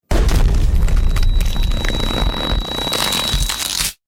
Neck Snap Sound Button - Free Download & Play
Sound Effects Soundboard27 views